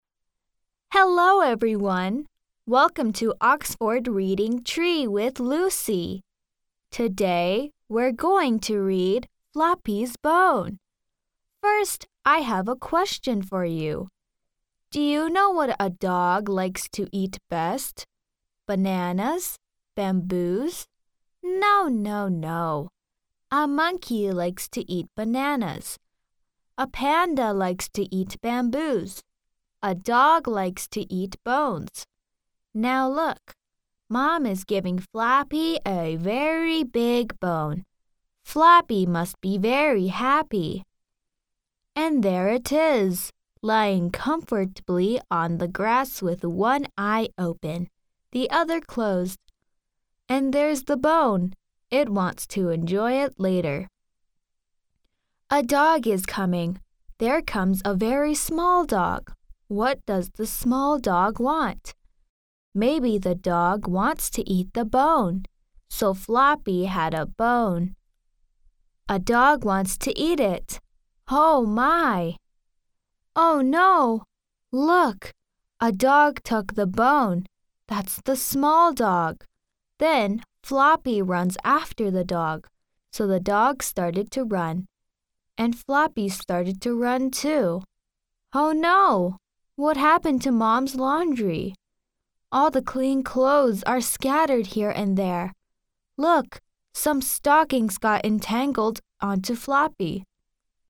标签： 浑厚
配音风格： 磁性 舒缓 浑厚 亲切